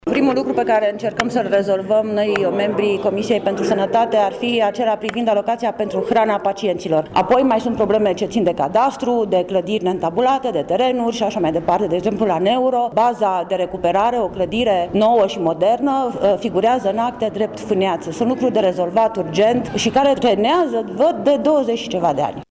Printre problemele identificate se numără şi unele a căror rezolvare este legată de intervenţia statului, aşa cum este alocaţia pentru hrana pacienţilor, de numai 7 lei pe zi şi chiar de calitatea hranei, aşa după cum precizează Mariana Sebeni, membru al Comisiei: